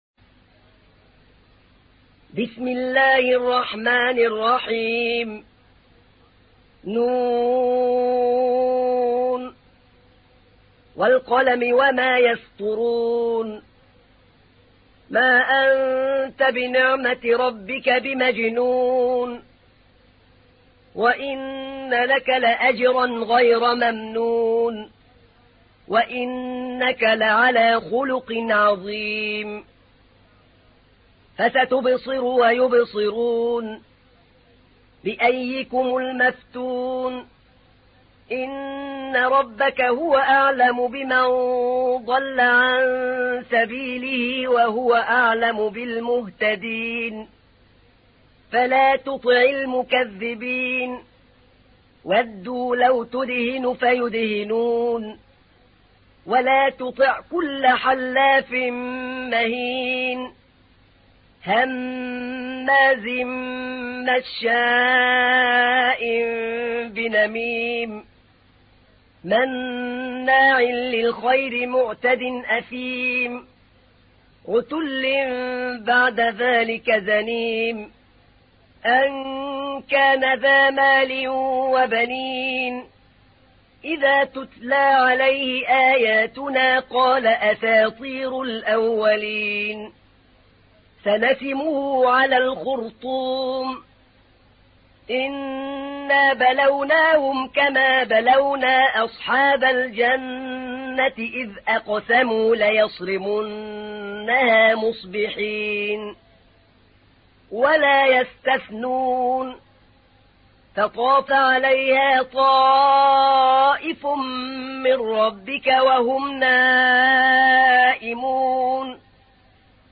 تحميل : 68. سورة القلم / القارئ أحمد نعينع / القرآن الكريم / موقع يا حسين